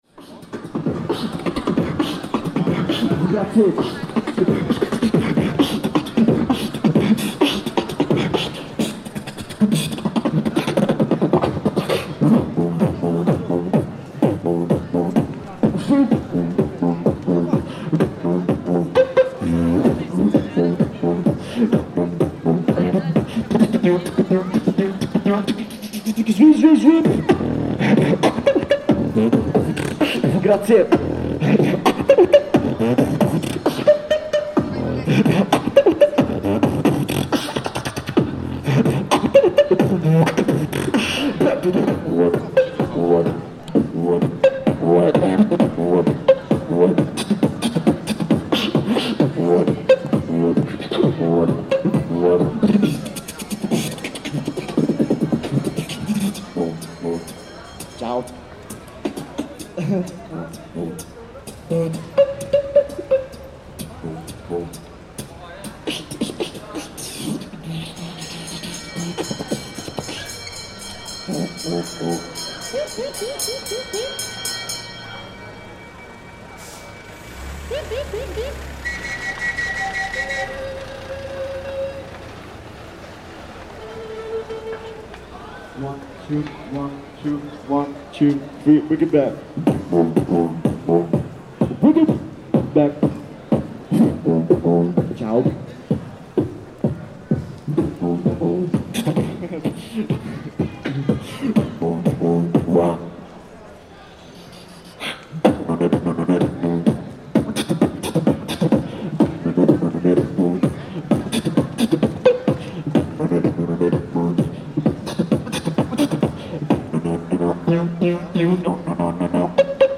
Beatboxing on Christmas Eve
A very talented beatboxer performs on the streets of Padova, Italy on Christmas Eve 2023 - you can hear his beats and scratches, and between that his words of appreciation for people supporting his street music.